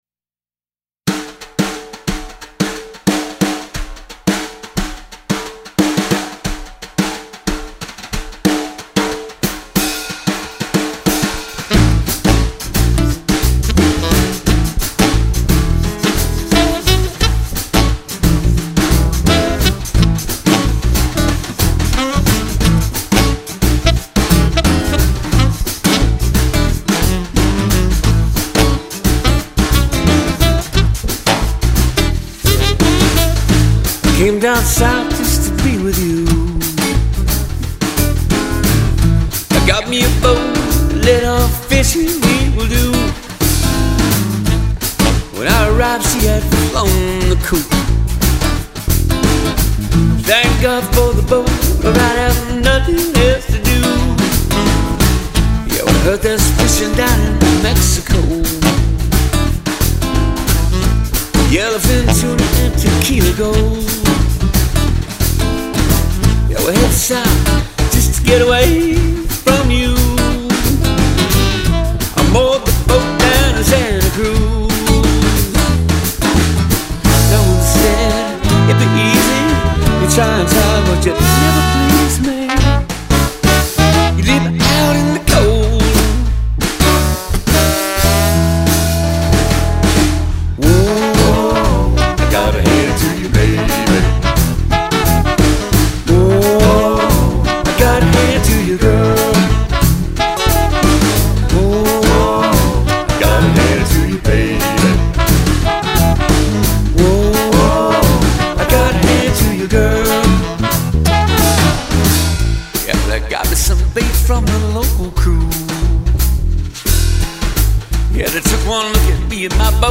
American Roots
horns
New Orleans and bebop come into the foreground.